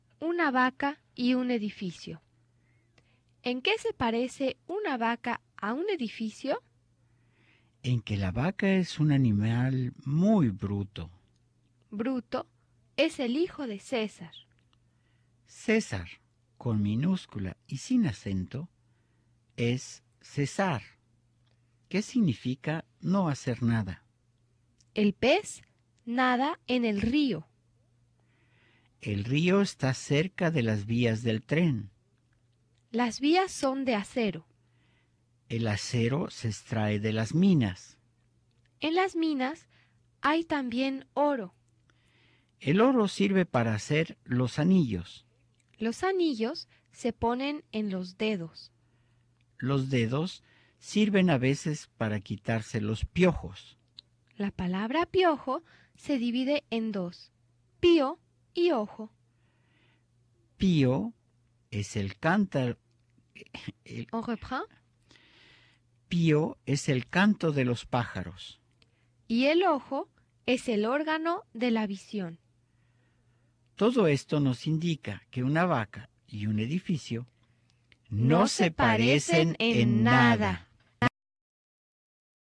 Ejercicios de pronunciación